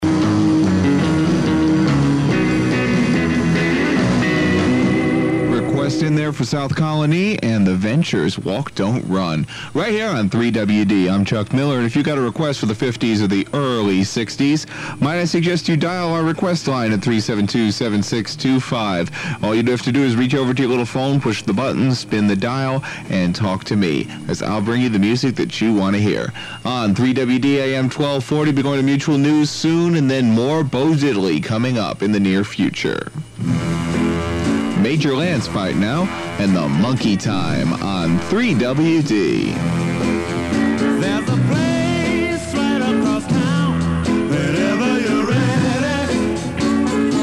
The sound quality is poor, and I don’t remember if I recorded them just to hear how I sounded back in the day, or if I thought by putting them together I could create an audio resume for a future radio job.
And unlike my time with WHCL, I actually have some airchecks of my work available for you to hear how I sounded back in the day.